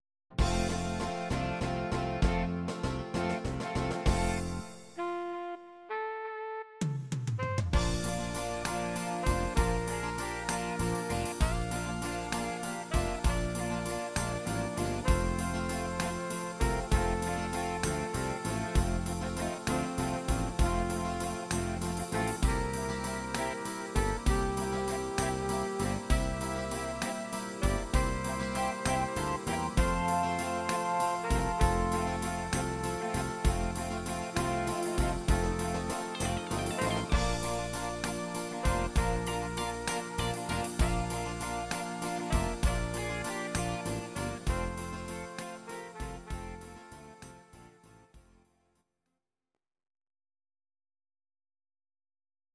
Audio Recordings based on Midi-files
Our Suggestions, Oldies, German, 1960s